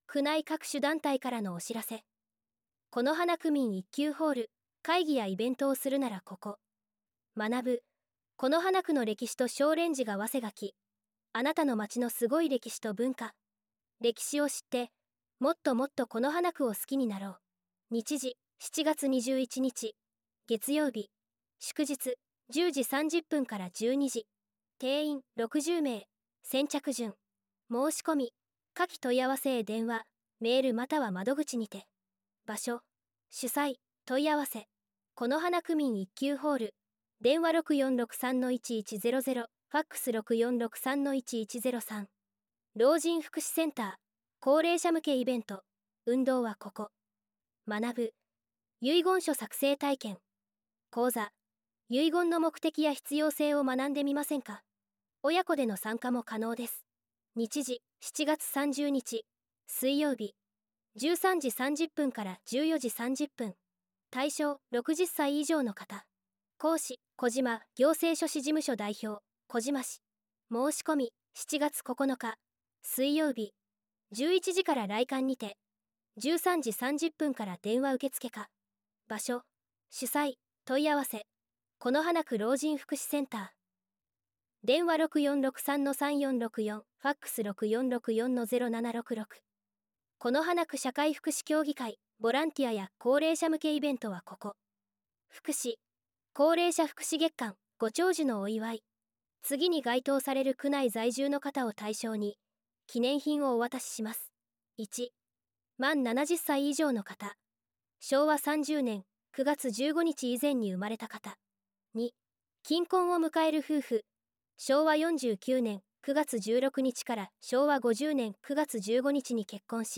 音声版　広報「このはな」令和7年7月号